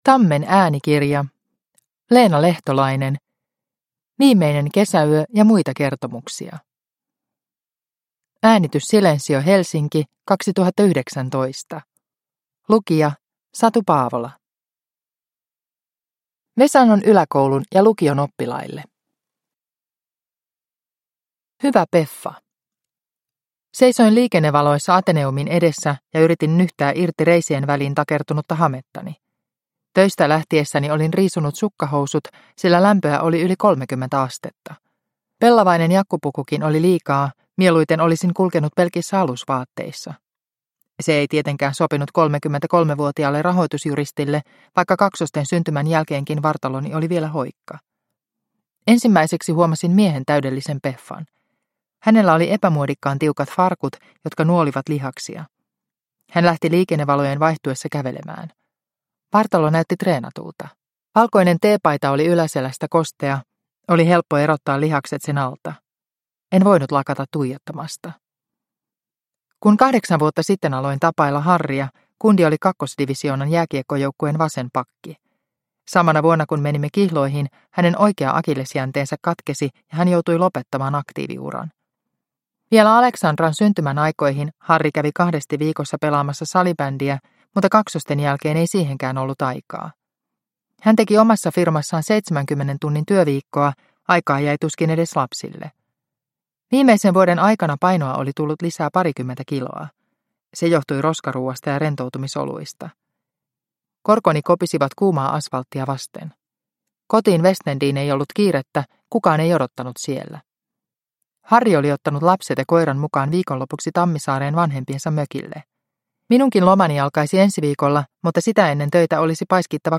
Viimeinen kesäyö – Ljudbok